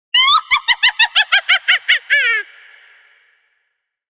Witch-cackle-sound-effect.mp3